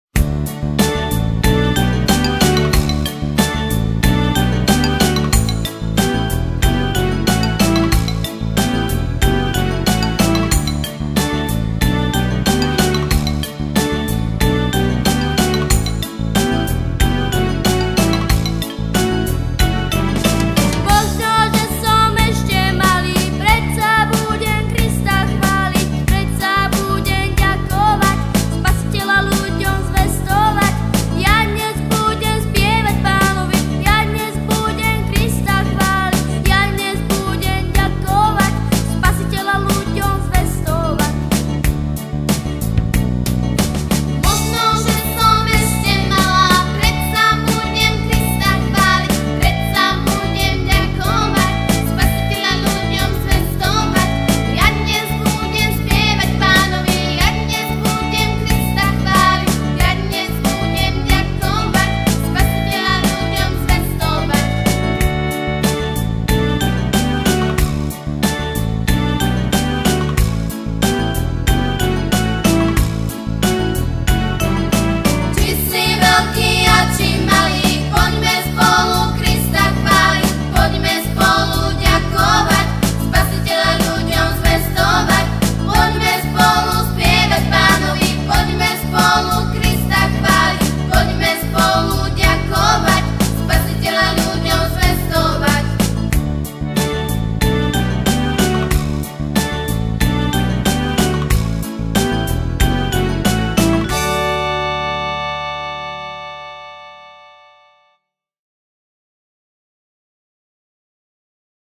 Křesťanské písně
Písně zpívané slovensky